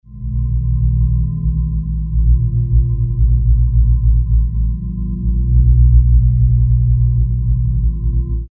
warp_test.mp3